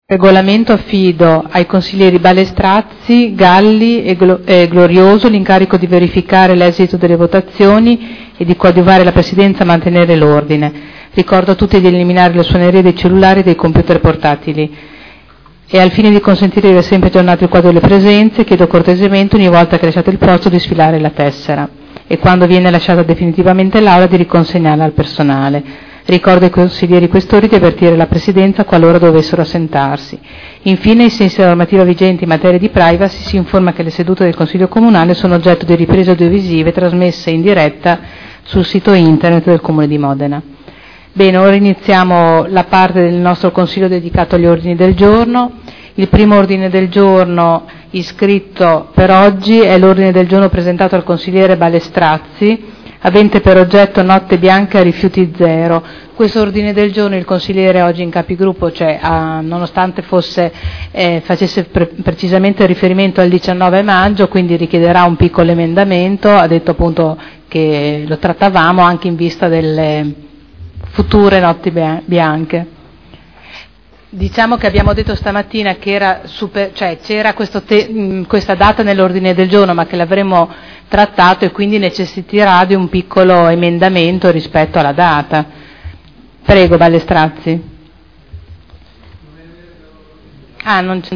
Seduta del 28/05/2012 Il Presidente Caterina Liotti apre i lavori del Consiglio.